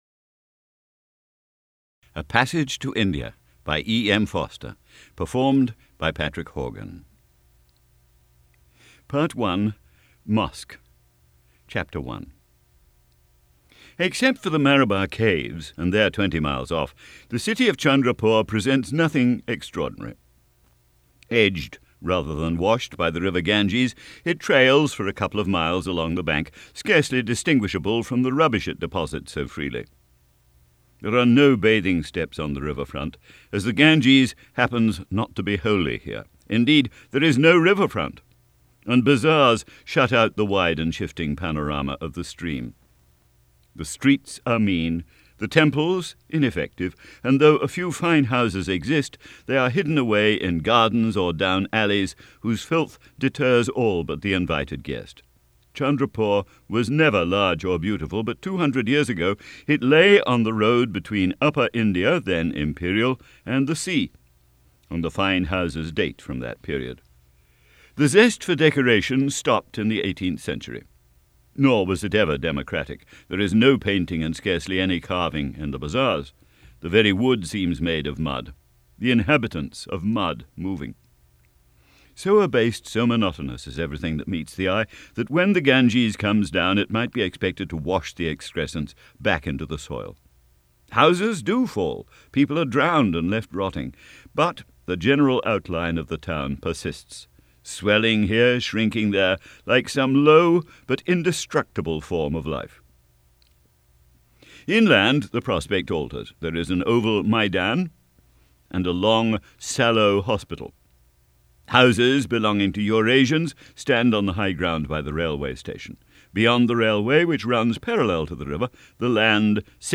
A Passage to India by E. M. Forster - audiobook mp3 d/l